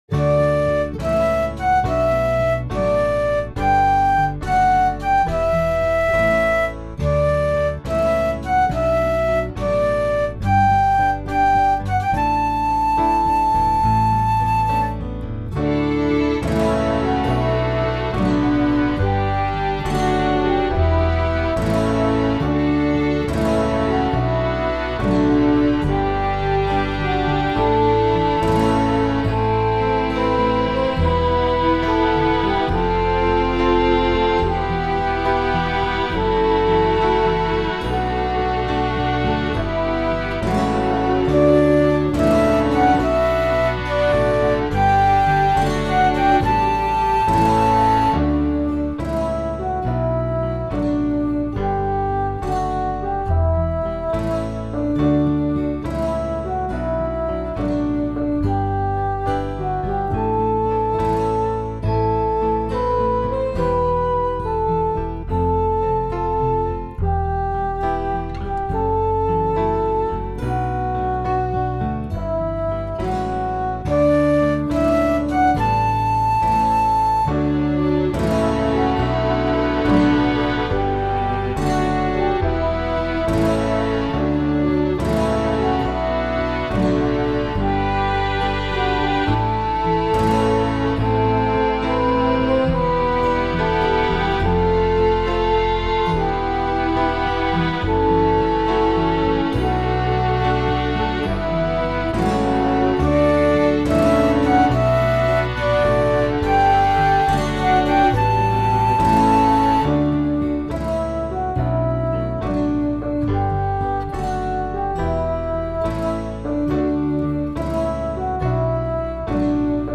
My backing increases the tempo just a little.